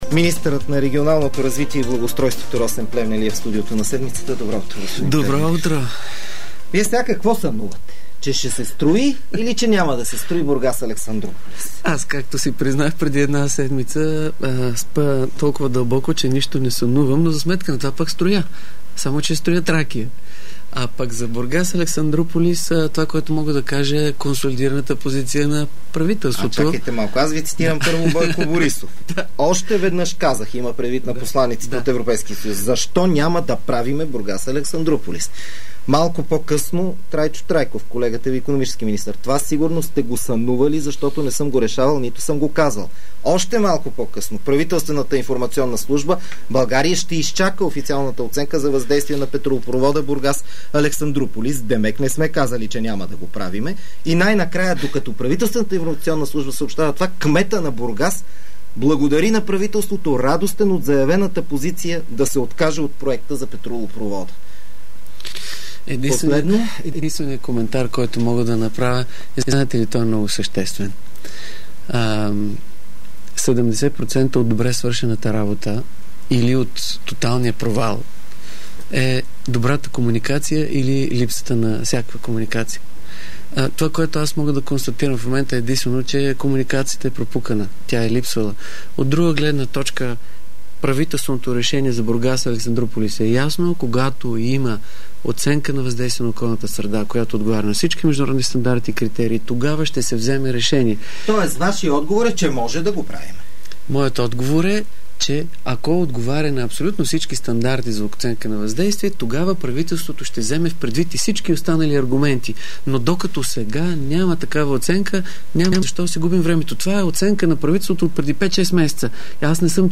Интервю с министър Росен Плевнелиев в „Седмицата” по Дарик